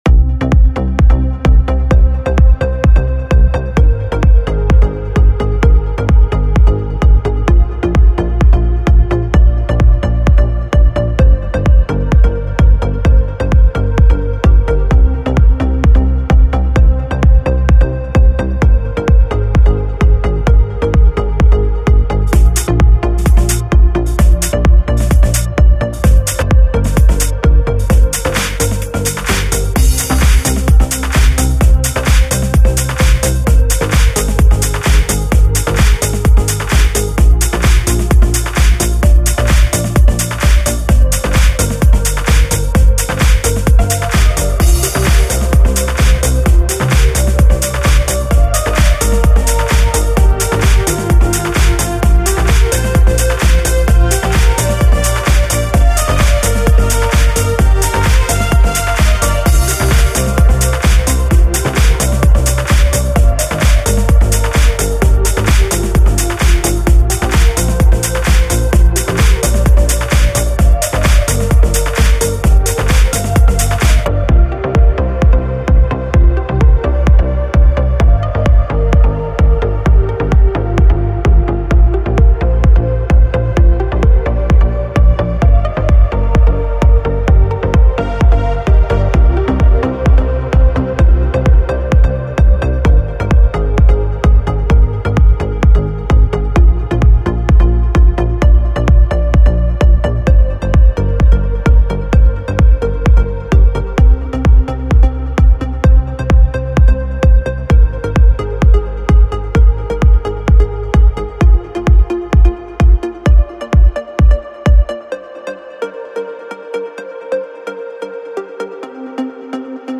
New example MIDI Tracker track - playing it LIVE in real-time:
MP3 Music file (2.2M) - Live recording MP3